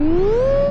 sci-fi_alarm_warning_loop_03.wav